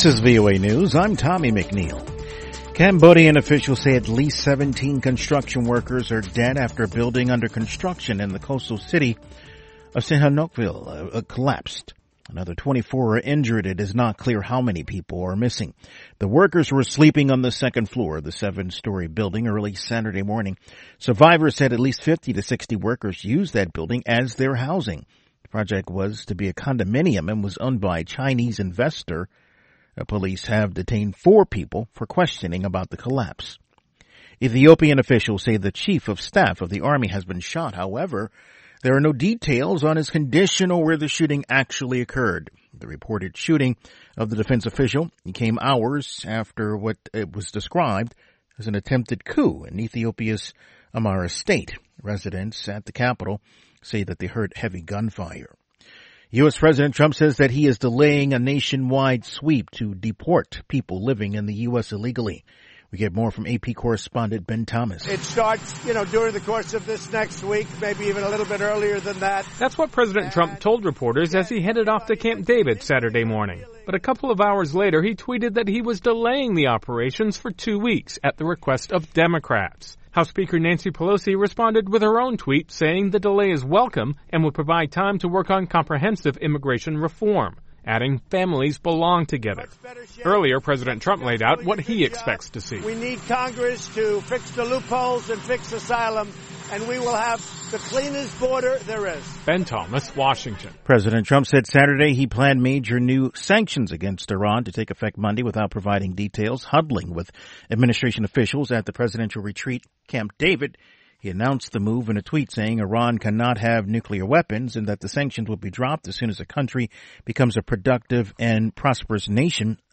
We bring you reports from our correspondents and interviews with newsmakers from across the world.
Tune in at the top of every hour, every day of the week, for the 5-minute VOA Newscast.